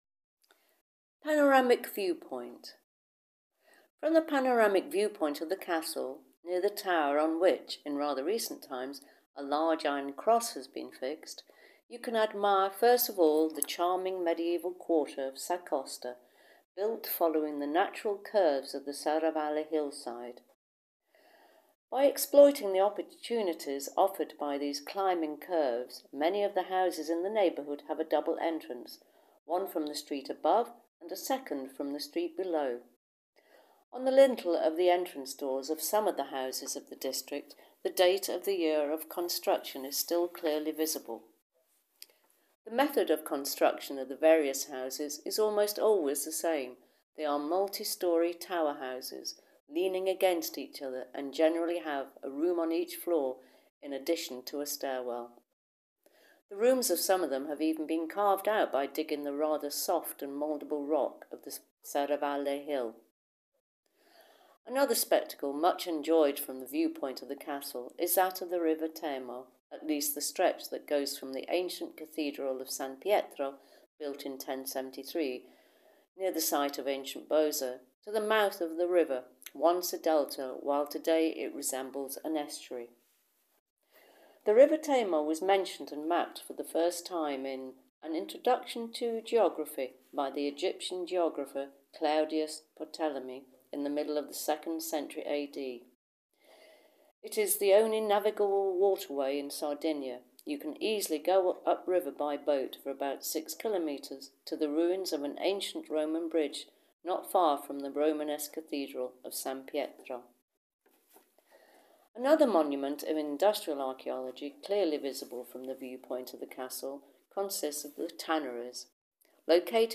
Audioguide - Audioguides